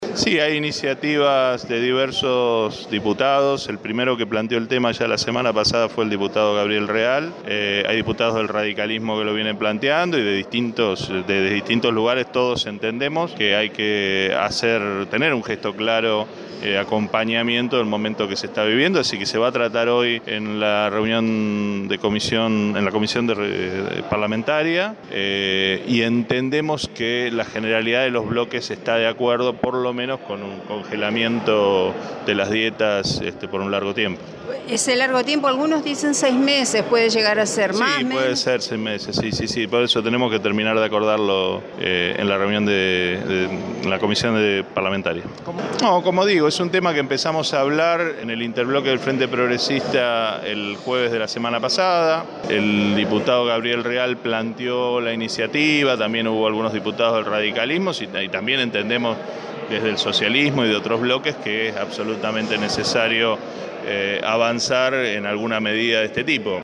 El diputado del Frente Progresista, Pablo Farías habló con Radio EME y confirmó que la idea ya se está trabajando.